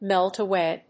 アクセントmélt awáy